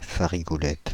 Ääntäminen
Synonyymit thym farigoule Ääntäminen France (Île-de-France): IPA: /fa.ʁi.ɡu.lɛt/ Haettu sana löytyi näillä lähdekielillä: ranska Käännöksiä ei löytynyt valitulle kohdekielelle.